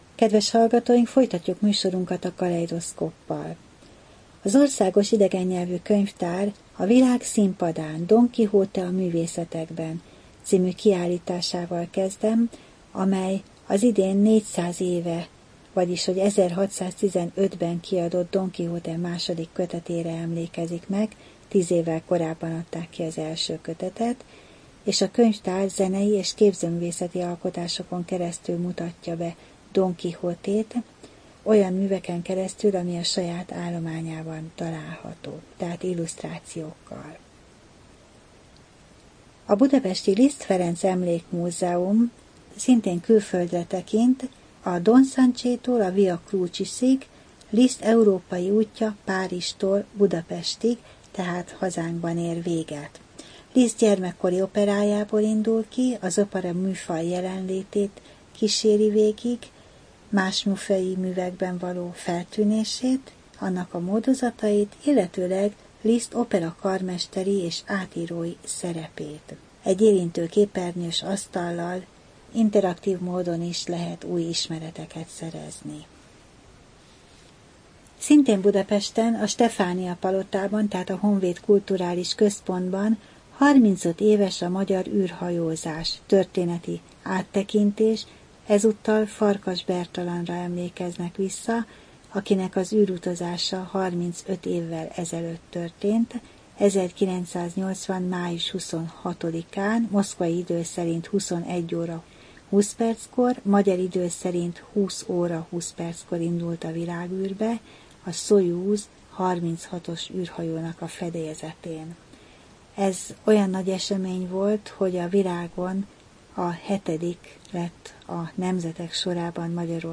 Rádió: Tárlatról tárlatra Adás dátuma: 2015, May 21 Tárlatról tárlatra / KONTAKT Rádió (87,6 MHz) 2015. május 21. A műsor felépítése: I. Kaleidoszkóp / kiállítási hírek II. Bemutatjuk / Múzeumok majálisa A műsor vendége